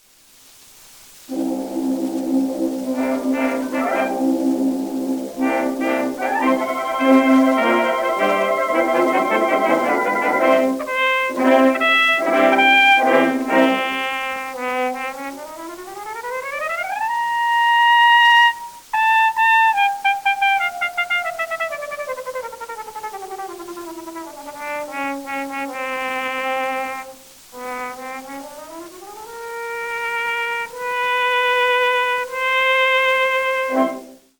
CORNET
original double-sided recordings made 1900-1922